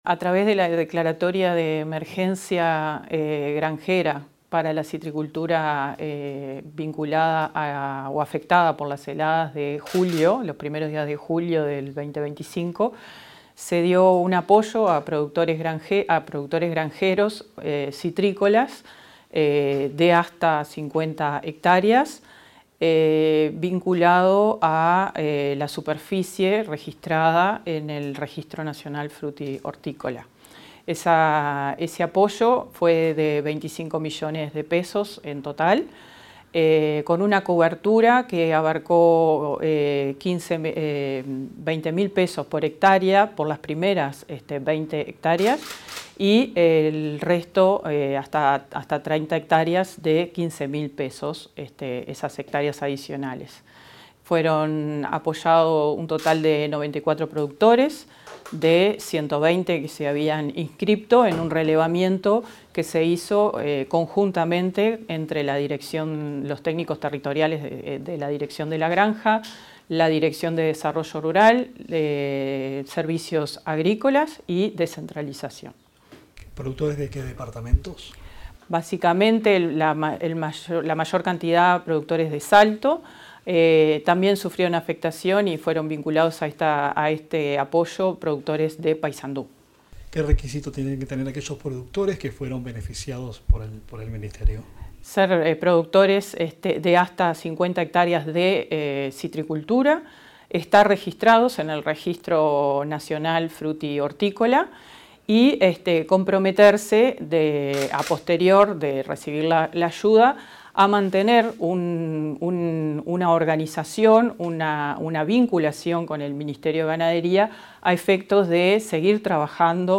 Declaraciones de la directora general de la Granja, Laura González